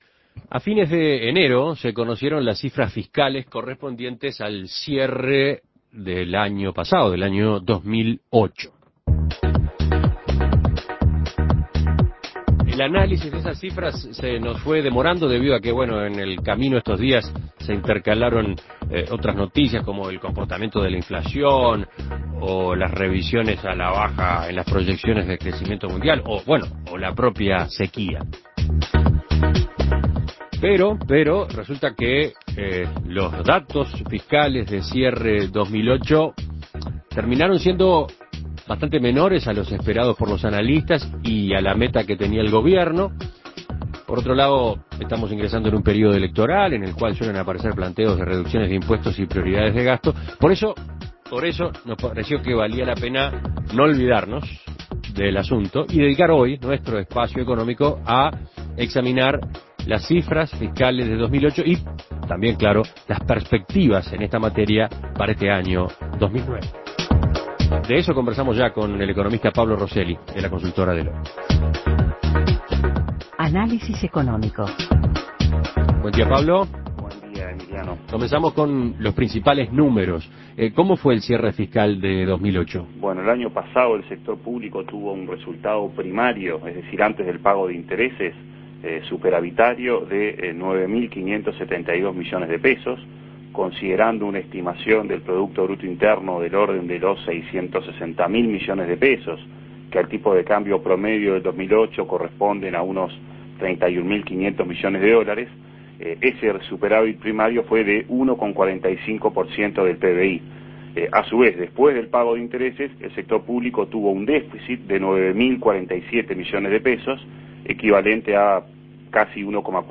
Análisis Económico ¿Cómo son las perspectivas en materia fiscal tras conocerse el cierre de 2008?